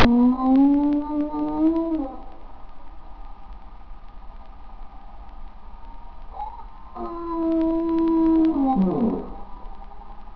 Whaletru
WHALETRU.wav